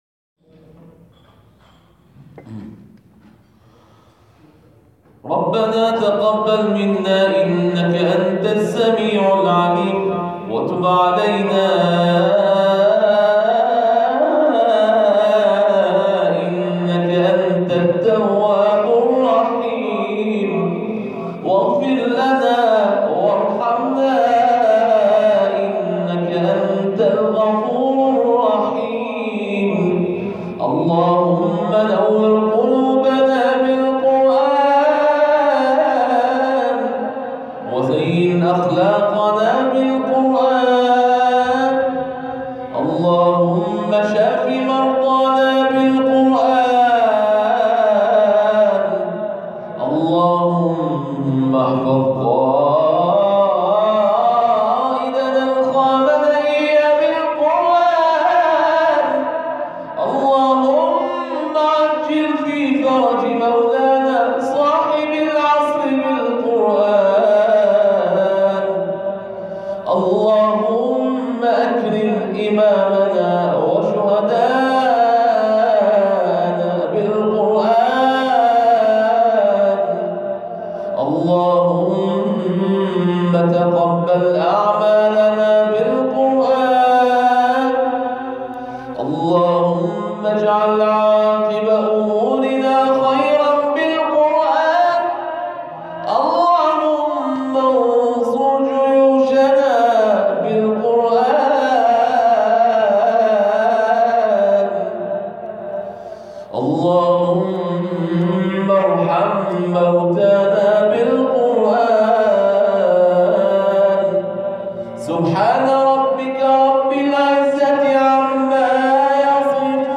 دعای ختم قرآن